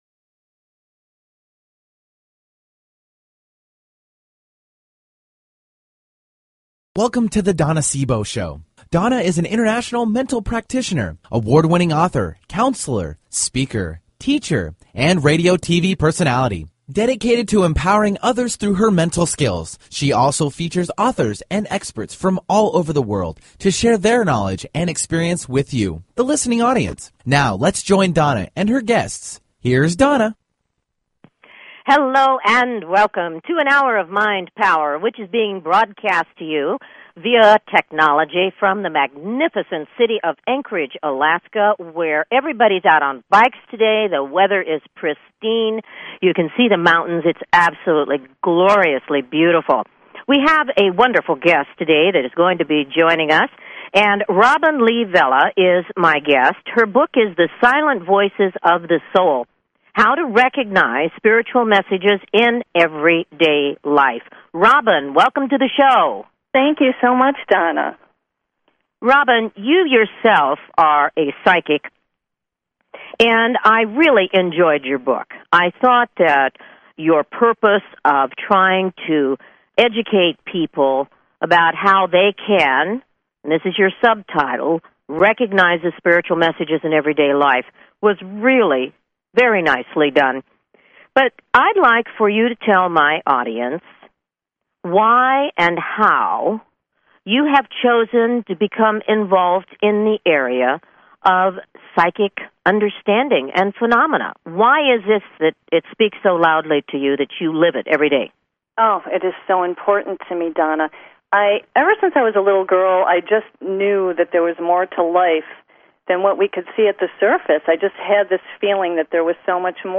Talk Show Episode
Her interviews embody a golden voice that shines with passion, purpose, sincerity and humor.
Tune in for an "Hour of Mind Power". Callers are welcome to call in for a live on air psychic reading during the second half hour of each show.